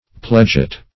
Pledget \Pledg"et\, n. [Prov. E., a small plug.]